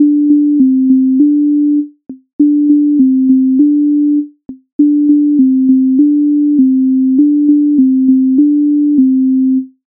Вийди вийди сонечко Українська народна пісня